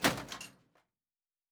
pgs/Assets/Audio/Sci-Fi Sounds/MISC/Metal Foley Impact 3.wav at master
Metal Foley Impact 3.wav